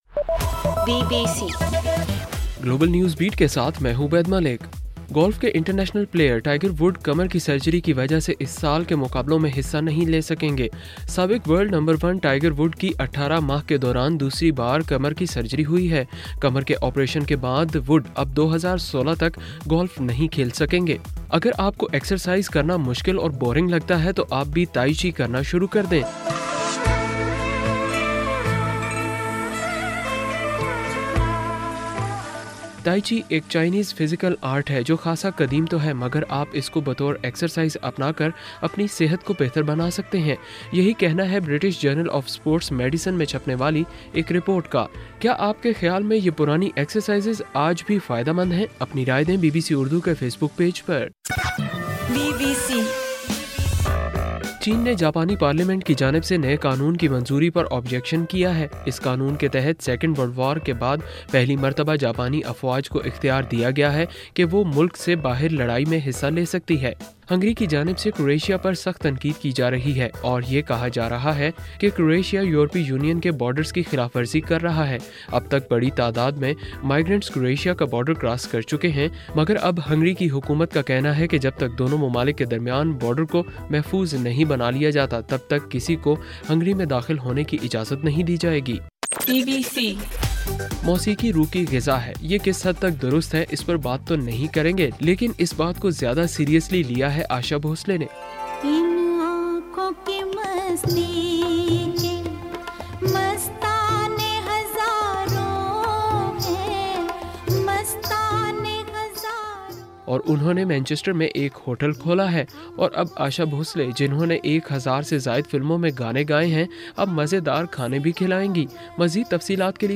ستمبر 19: رات 9 بجے کا گلوبل نیوز بیٹ بُلیٹن